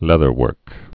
(lĕthər-wûrk)